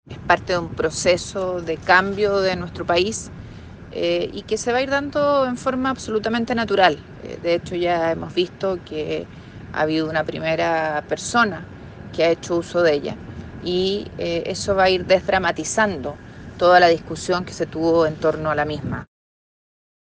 La senadora de la Democracia Cristiana y patrocinadora de la moción, Ximena Rincón, sostuvo que la entrada en vigencia de la ley irá “desdramatizando” la discusión en torno al tema.